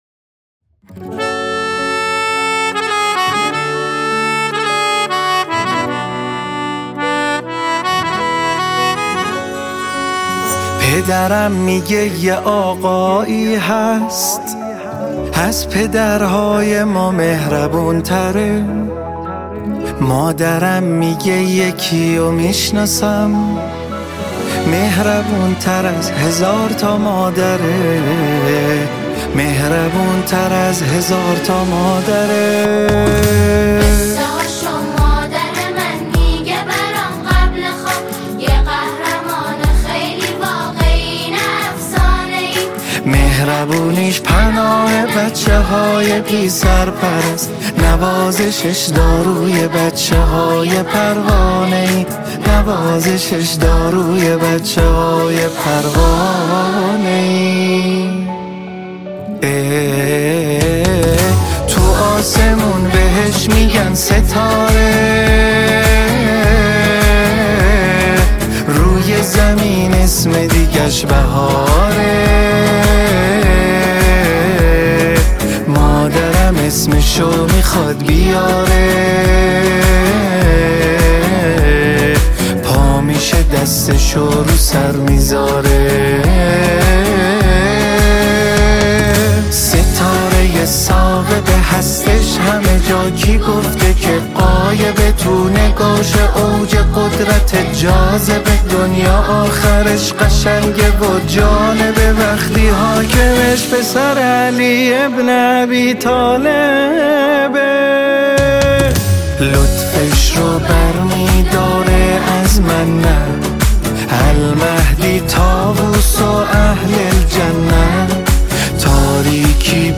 فضای جشنی پرشور را ترسیم کند
ژانر: سرود ، سرود کودک و نوجوان ، سرود مذهبی ، سرود مناسبتی